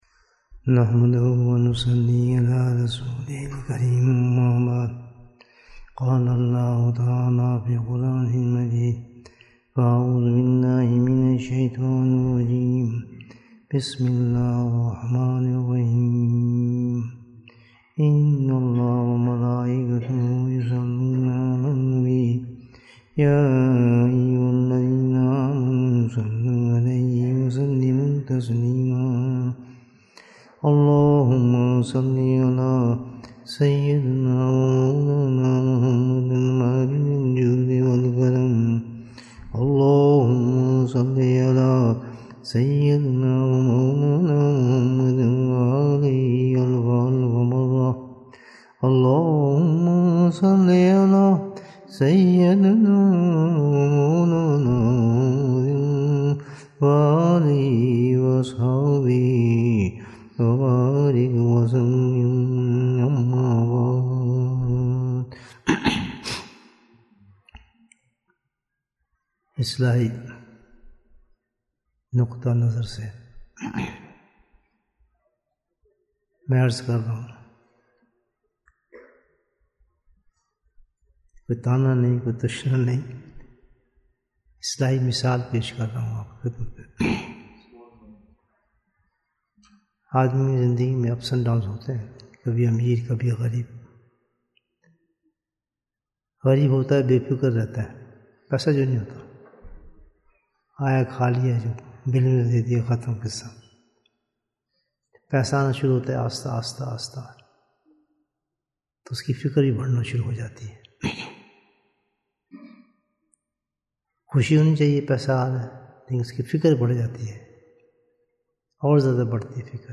Bayan, 62 minutes 8th December, 2022 Click for English Download Audio Comments Which is the Lock for the Safety of Deeds?